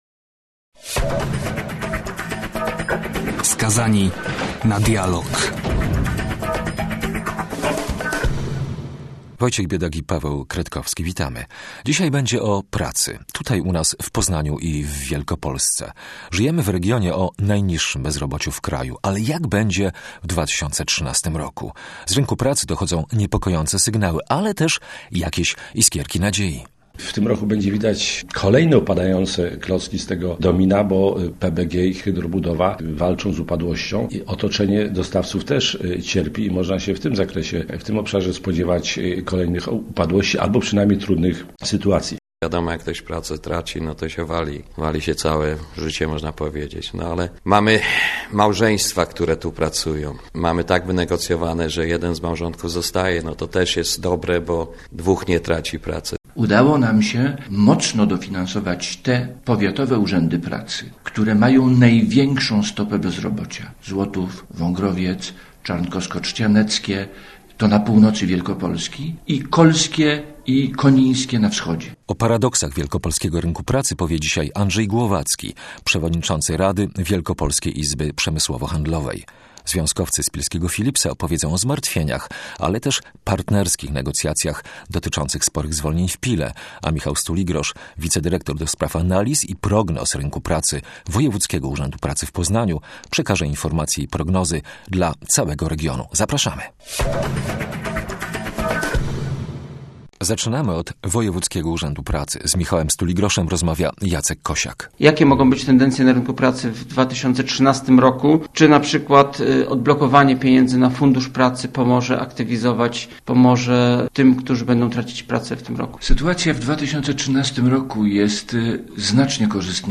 Reportaże - debaty - wywiady.